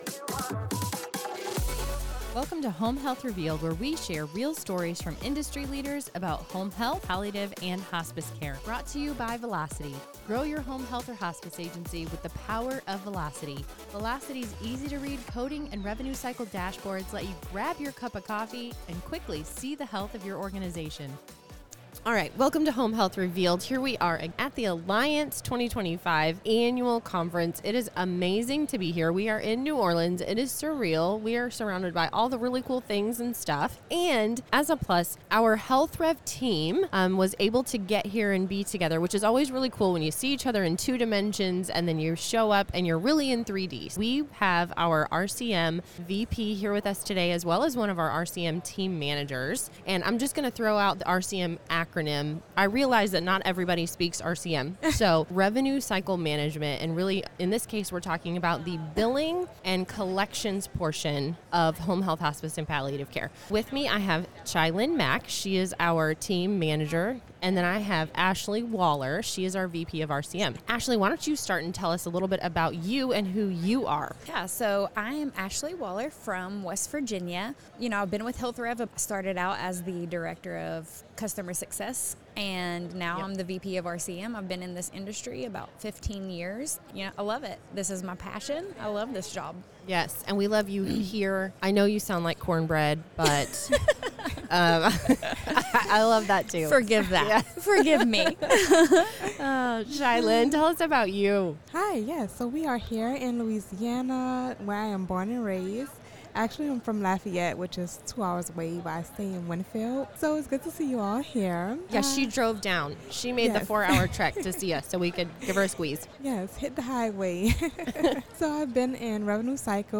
Recorded live at the Alliance Conference, this episode sets the tone for a series exploring innovation, collaboration, and success acros the post-acute care industry.